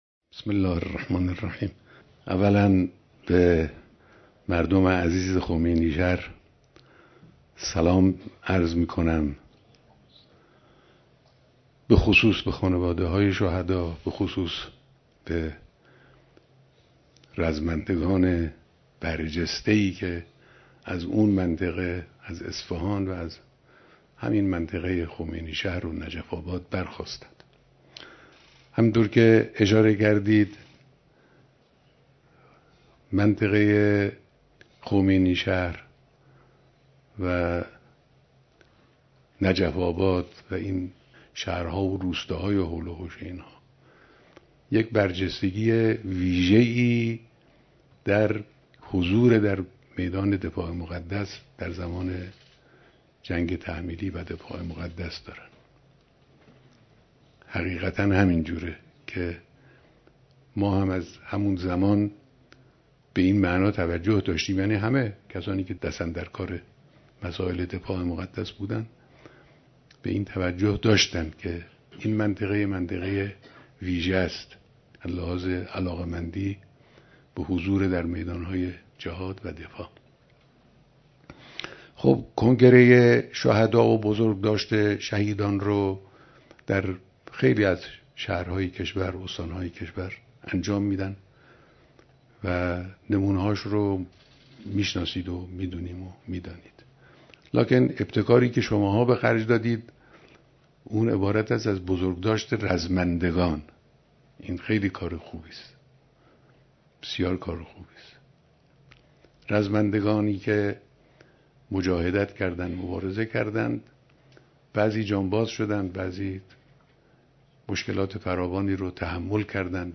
بیانات در دیدار اعضای ستاد برگزاری اجتماع 15 هزار نفری رزمندگان خمینی‌شهر